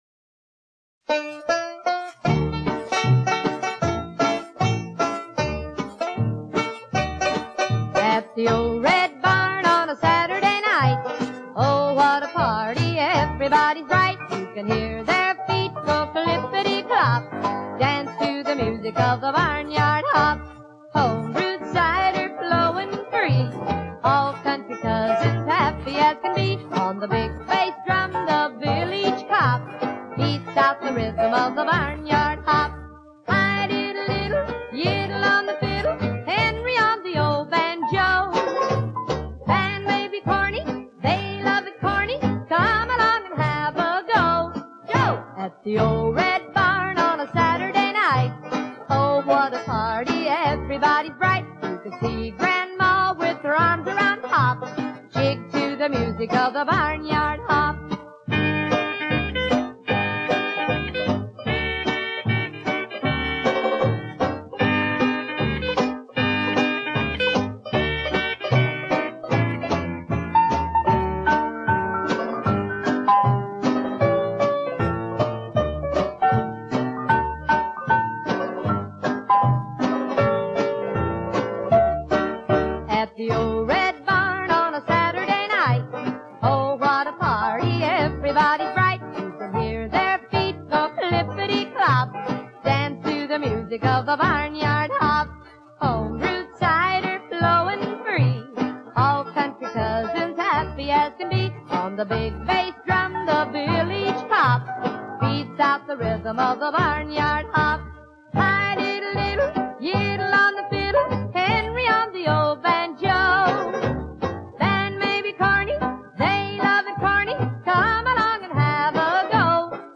a beautiful lady with a fine voice.